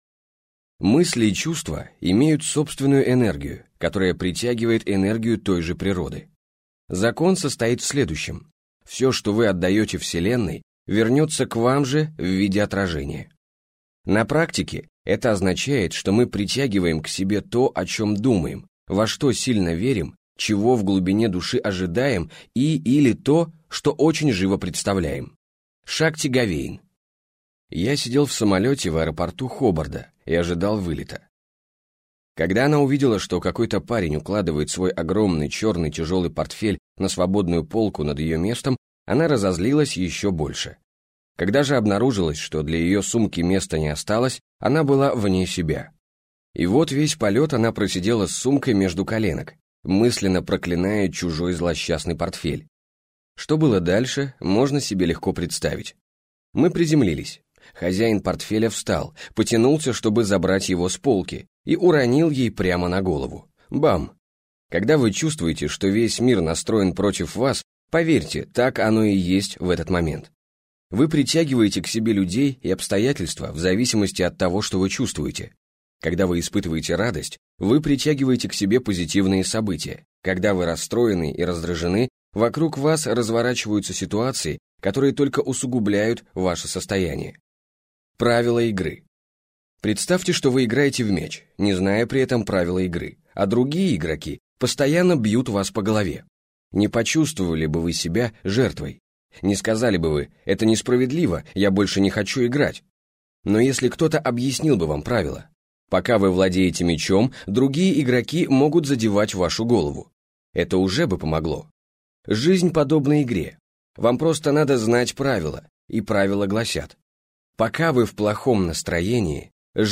Аудиокнига Магнит счастья. Как привлечь в свою жизнь все, что хочешь - купить, скачать и слушать онлайн | КнигоПоиск